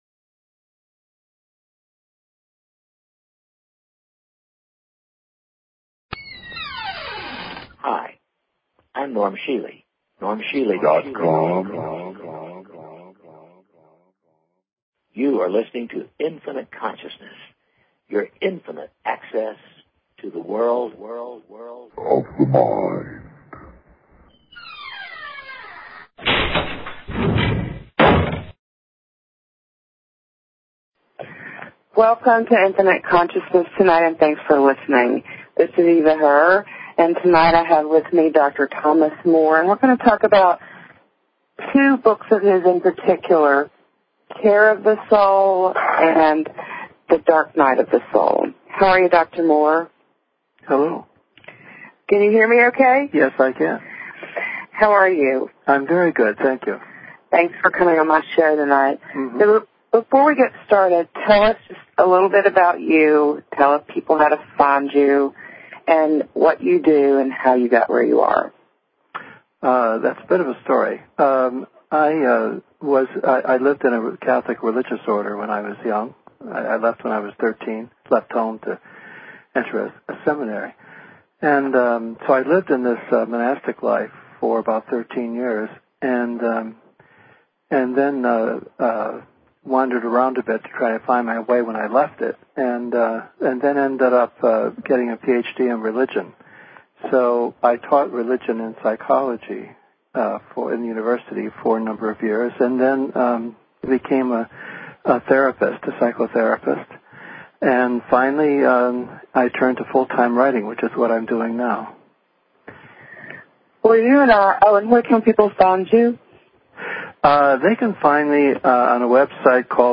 Talk Show Episode, Audio Podcast, The_Infinite_Consciousness and Courtesy of BBS Radio on , show guests , about , categorized as
Dr. Thomas Moore - Author: Care of the Soul; and Dark Night of the Soul